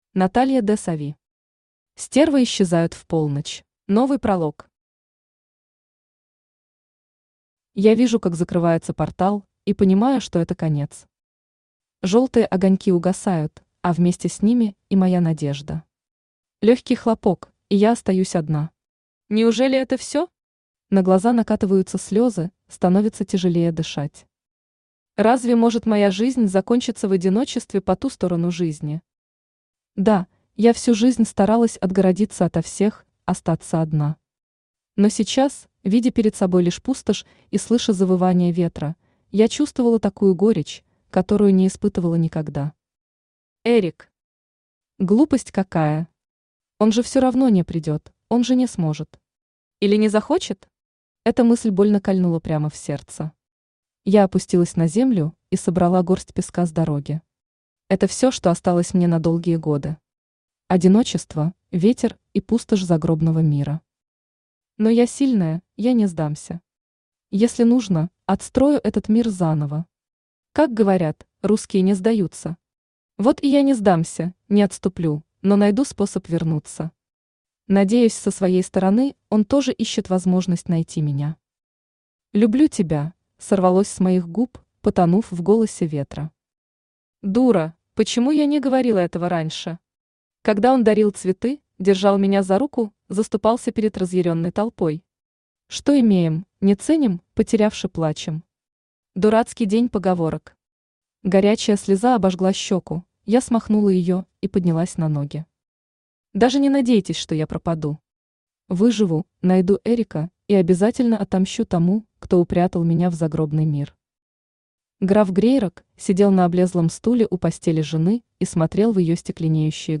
Аудиокнига Стервы исчезают в полночь | Библиотека аудиокниг
Aудиокнига Стервы исчезают в полночь Автор Наталья ДеСави Читает аудиокнигу Авточтец ЛитРес.